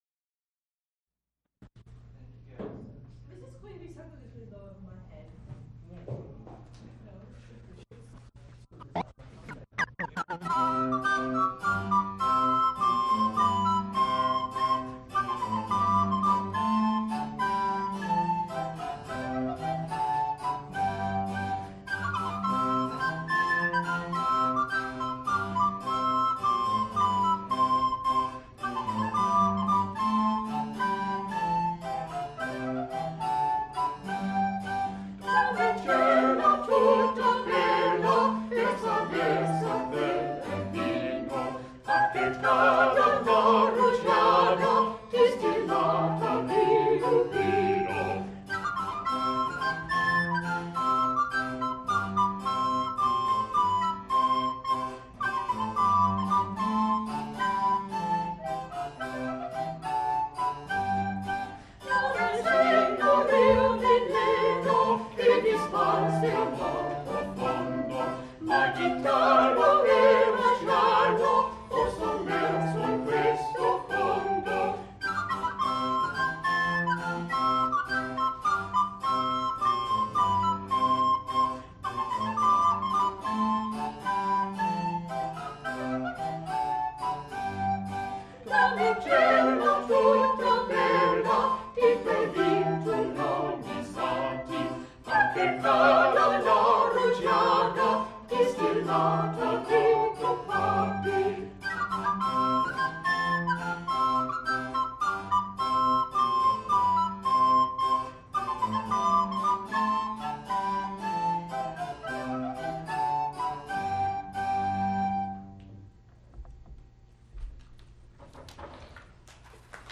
Recorded live April 12, 1978, Studio Theatre, University of Pittsburgh.
Madrigals, Italian Sacred songs (Low voice) with continuo